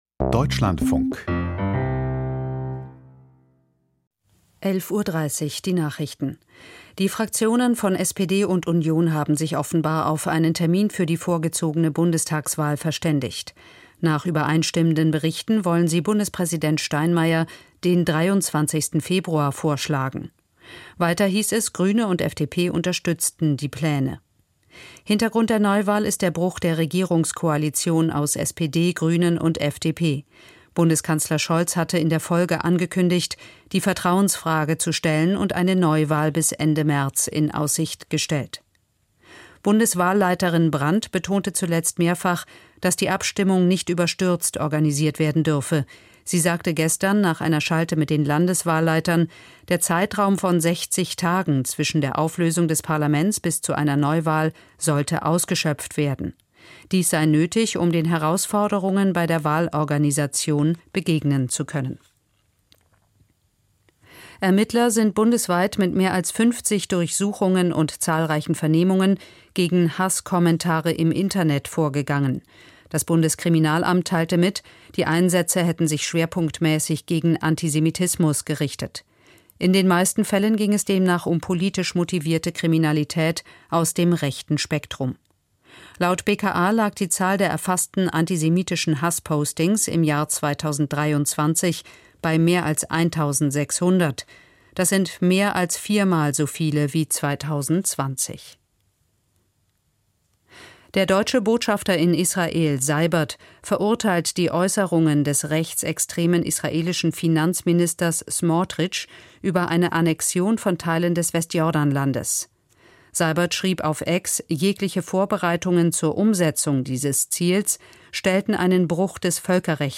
Die Deutschlandfunk-Nachrichten vom 12.11.2024, 11:30 Uhr